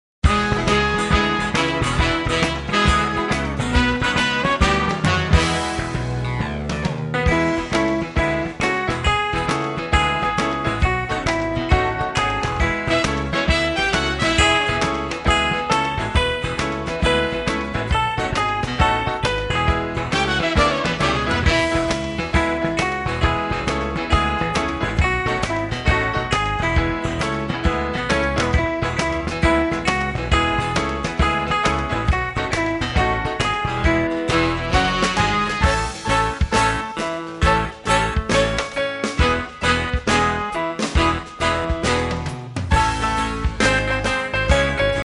Listen to a sample of the instrumental